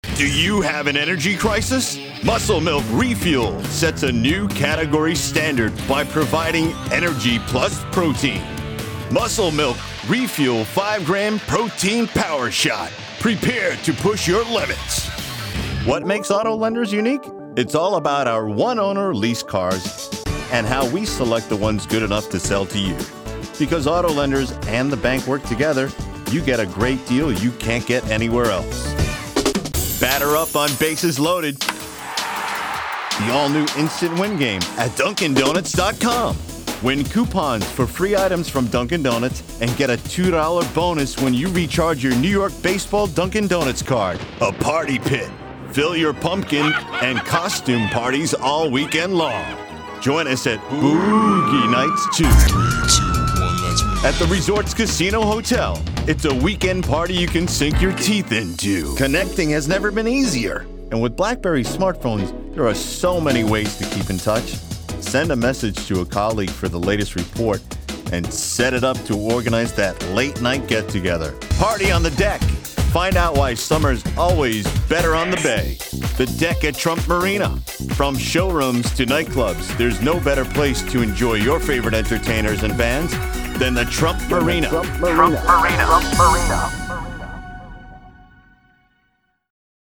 Kein Dialekt
Sprechprobe: Werbung (Muttersprache):